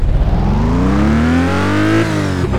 Index of /server/sound/vehicles/lwcars/lotus_esprit